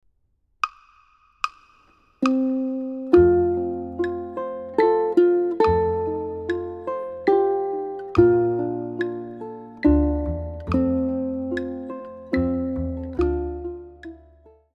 The pickup note leads into the downbeat and should be played a little softer than the downbeat.
pickup note